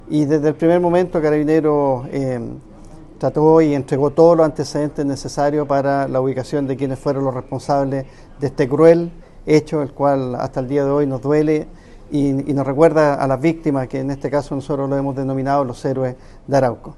Por otro lado, el general director de Carabineros, Marcelo Araya, se refirió al inicio del juicio, donde la institución ha colaborado en la investigación.